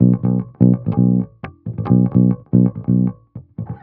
14 Bass Loop B.wav